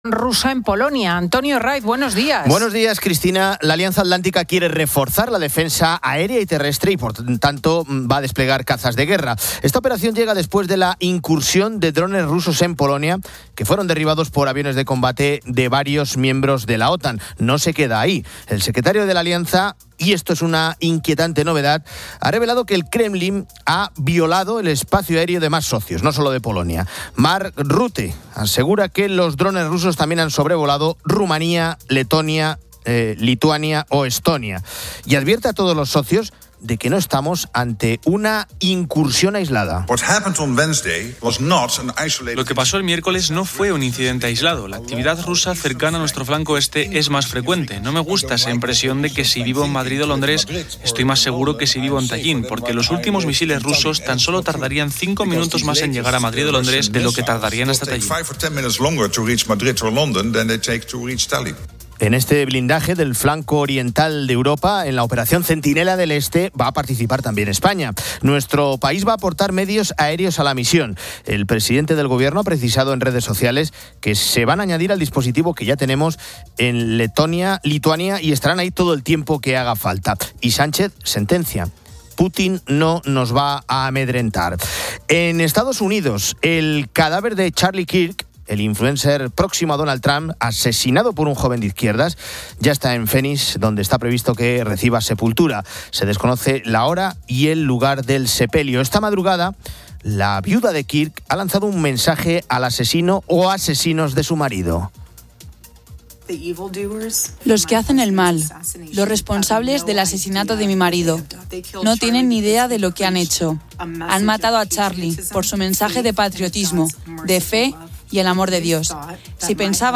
Fin de Semana 10:00H | 13 SEP 2025 | Fin de Semana Editorial de Cristina López Schlichting. Hablamos con la embajadora de Polonia en España, Monika Krzepkowska.